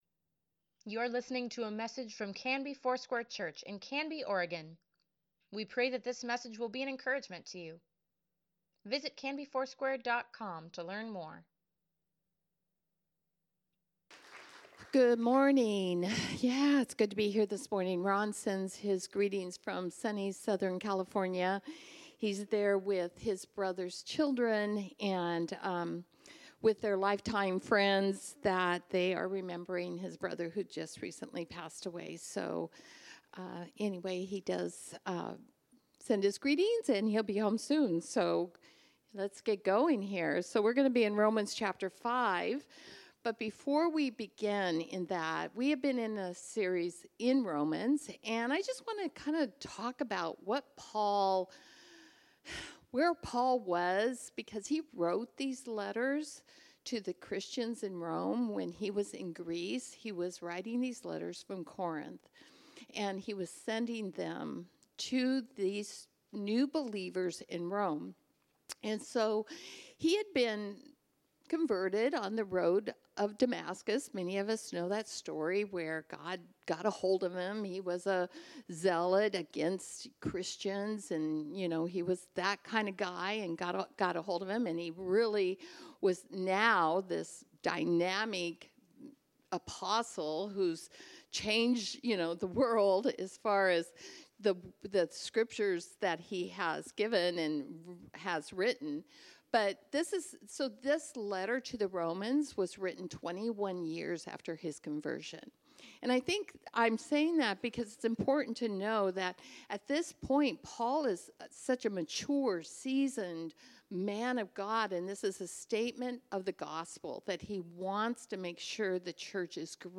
Sunday Sermon | April 28, 2024